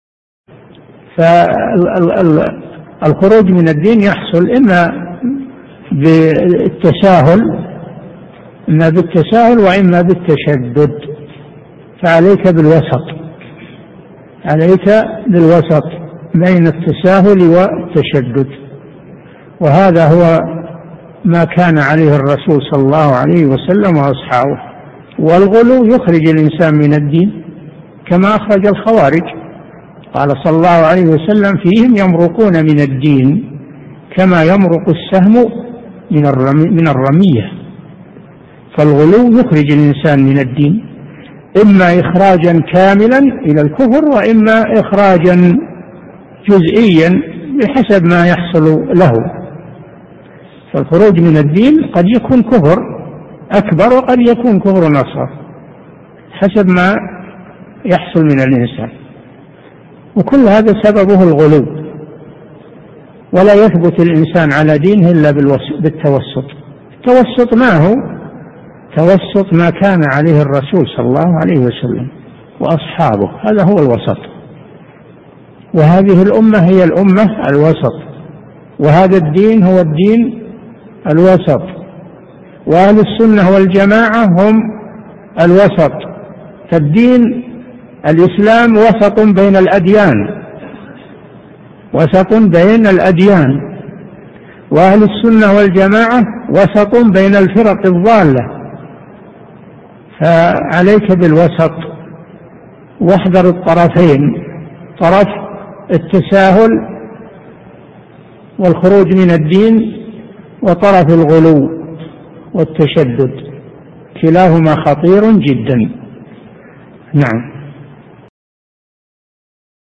Album: موقع النهج الواضح Length: 2:00 minutes (547.15 KB) Format: MP3 Mono 22kHz 32Kbps (VBR)